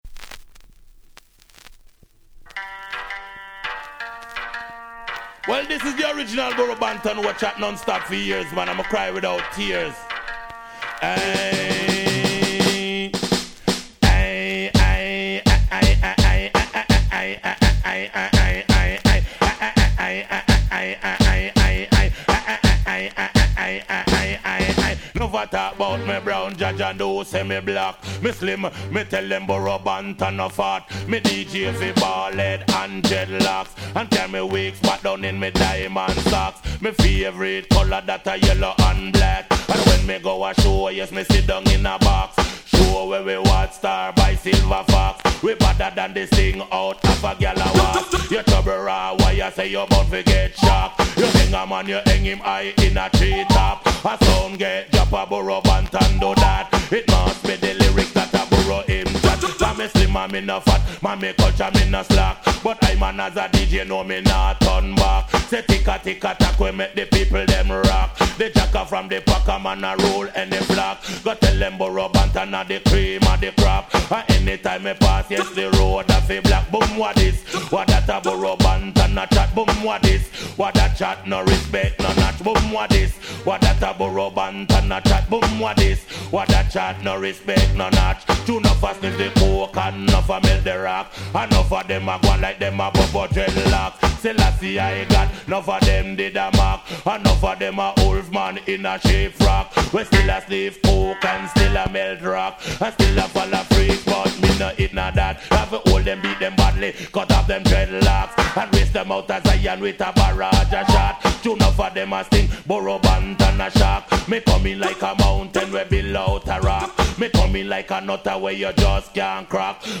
99' Super Hit Dancehall Reggae !!
問答無用のDancehall Classic !!
「アーイ、アーイ、アアイアアイ！」のサビでここ日本のClubでも大ヒット！！